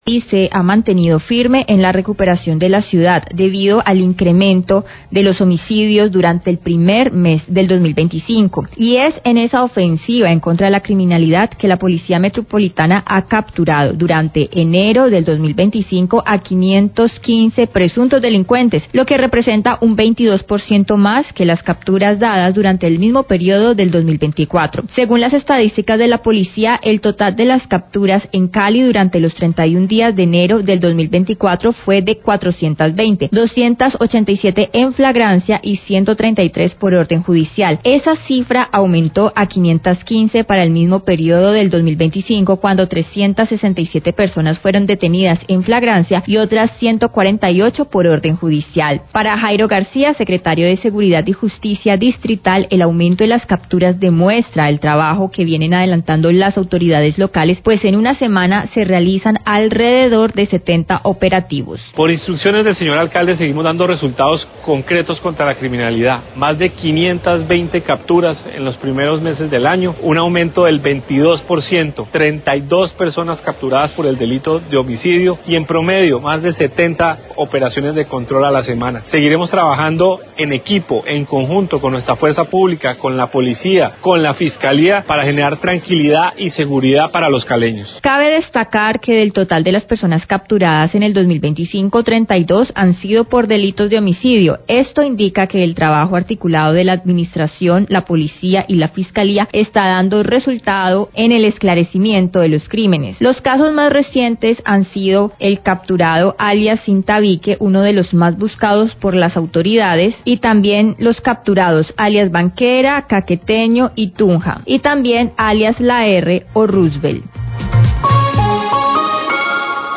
Radio
La administración de Cali sigue firme en cuestión de seguridad, Después del aumento de homicidios en el primer mes del año, en comparación al 2024, la administración ha puesto mayor énfasis en mantener y procurar la seguridad para los caleños. El secretario de Seguridad y Justicia habla al respecto.